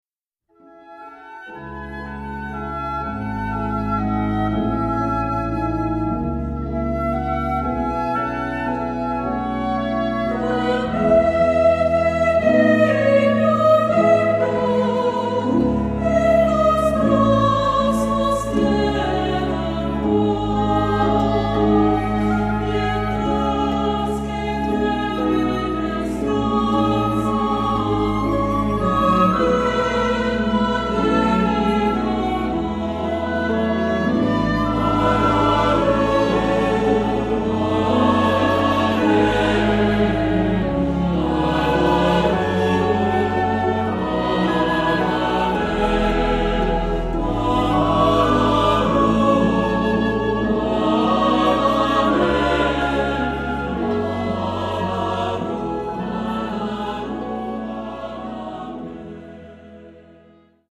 Accompaniment:      Organ, Flute;Oboe;Cello
Music Category:      Choral